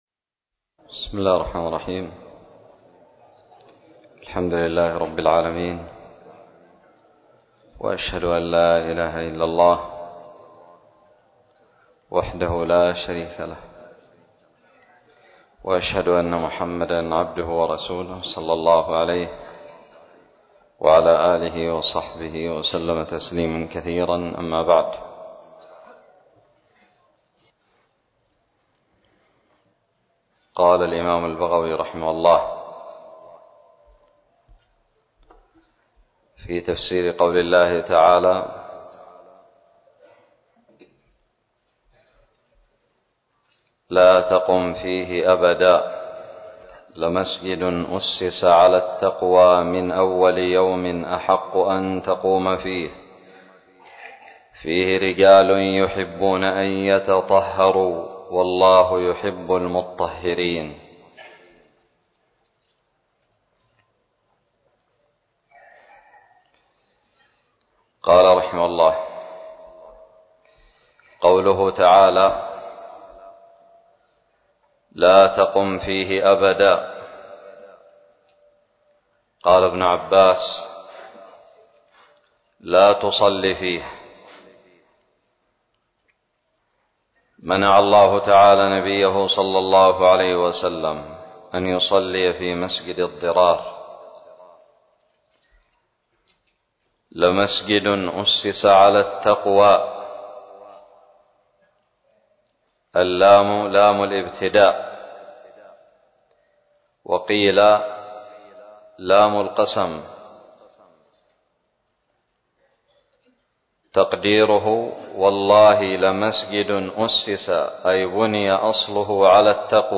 الدرس السادس والأربعون من تفسير سورة التوبة من تفسير البغوي
ألقيت بدار الحديث السلفية للعلوم الشرعية بالضالع